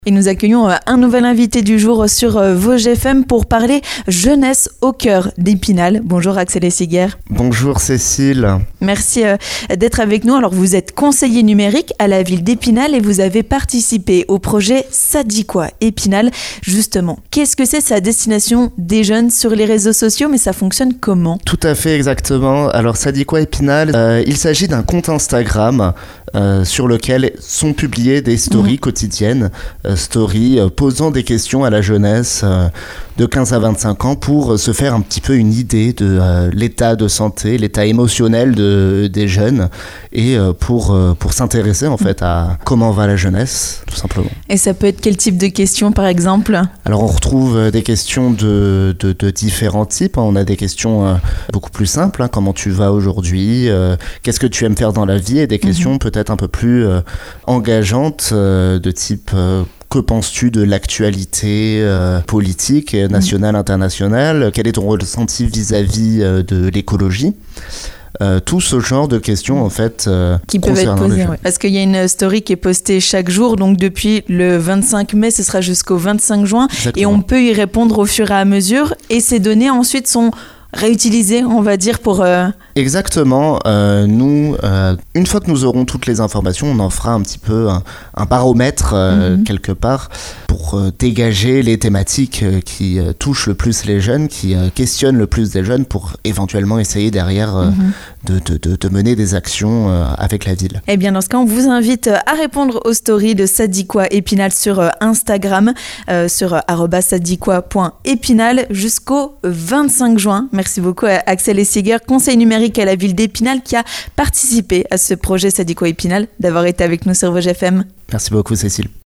3. L'invité du jour